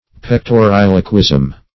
Pectoriloquism \Pec`to*ril"o*quism\, n.
pectoriloquism.mp3